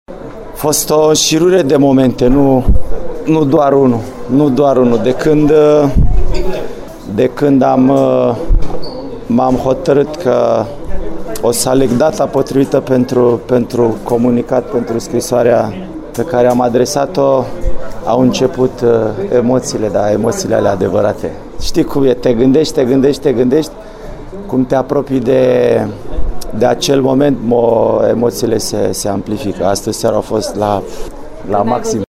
Lobonț a afirmat că de la momentul când a anunțat că se retrage a trăit o serie de emoții.